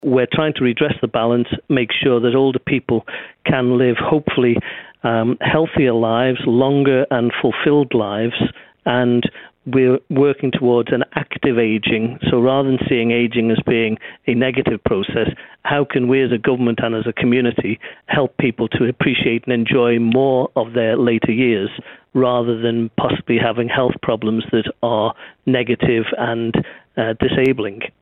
But in Tynwald last week, Mr Shimmin said it was time to change the debate about the ageing population.